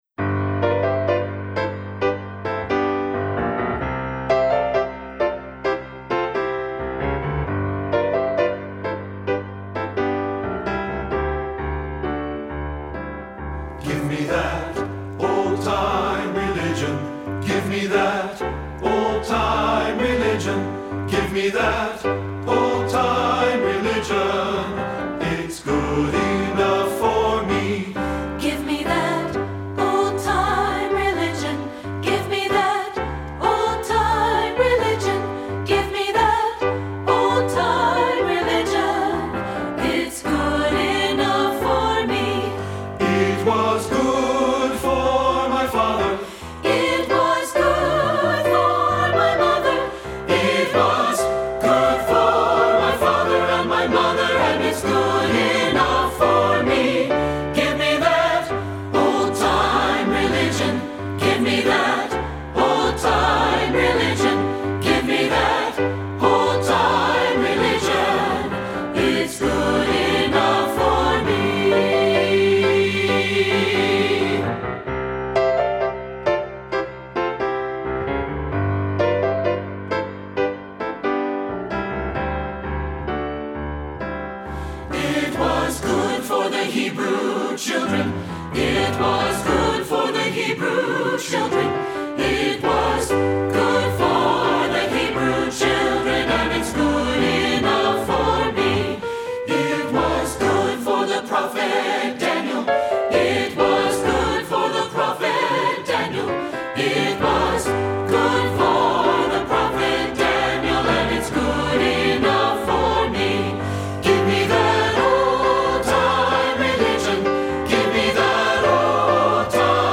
Composer: Traditional Spiritual
Voicing: SAB and Piano